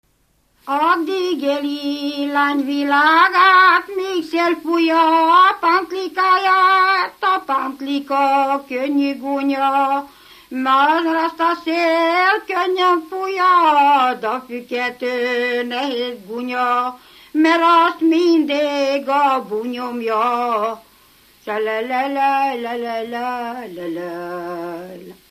Erdély - Kolozs vm. - Magyarpalatka
ének
Műfaj: Lassú cigánytánc
Stílus: 1.1. Ereszkedő kvintváltó pentaton dallamok